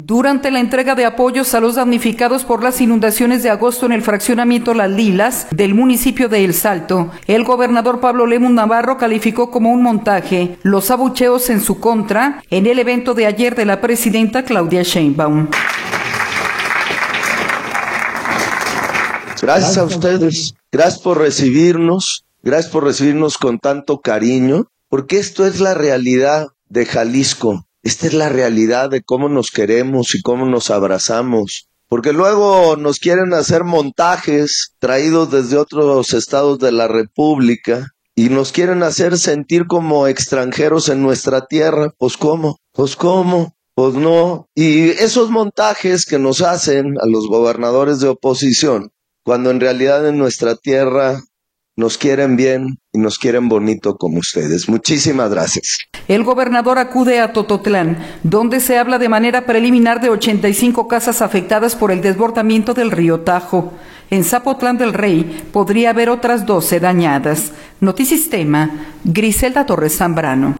Durante la entrega de apoyos a los damnificados por las inundaciones de agosto en el fraccionamiento Las Lilas del municipio de El Salto, el gobernador Pablo Lemus Navarro calificó como un montaje, los abucheos en su contra, durante el evento de ayer de la presidenta Claudia Sheinbaum.